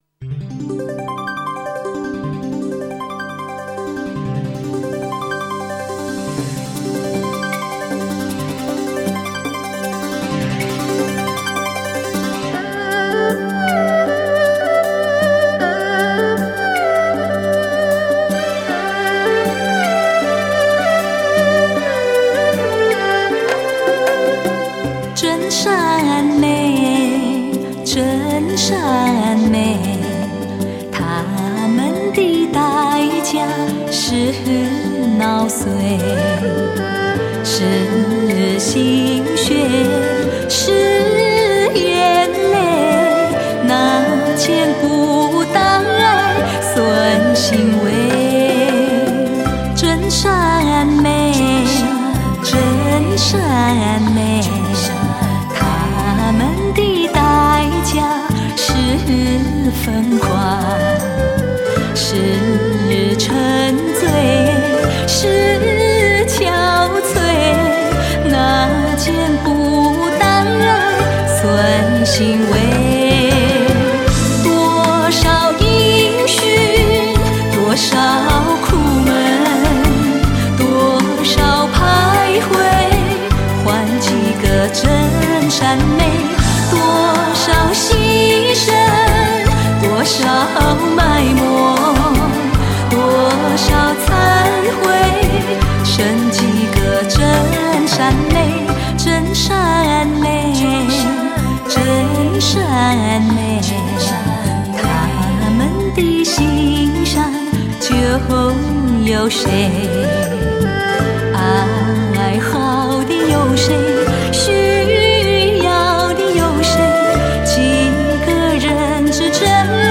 情愫缠绕 扣人心弦